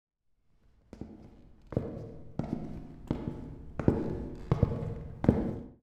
255081e1ee Divergent / mods / Soundscape Overhaul / gamedata / sounds / ambient / soundscape / underground / under_29.ogg 140 KiB (Stored with Git LFS) Raw History